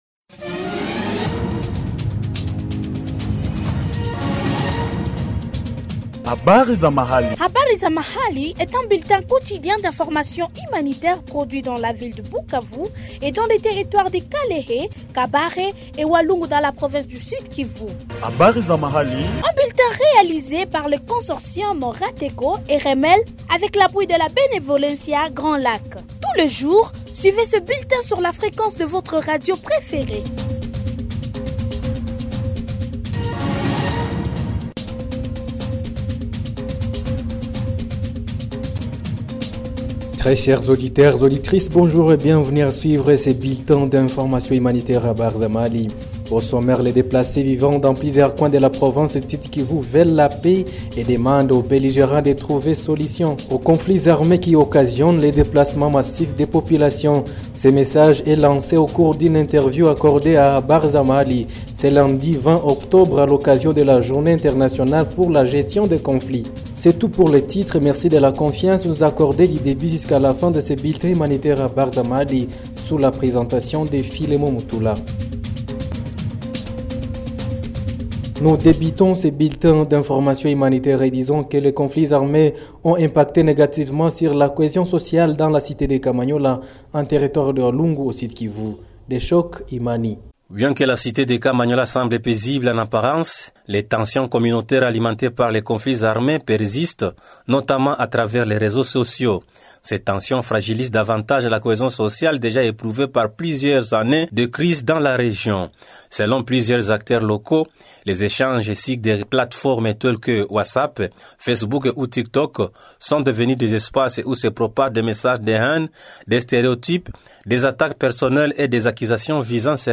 Retrouvez le bulletin Habari za mahali du 20 octobre 2025 produit au Sud-Kivu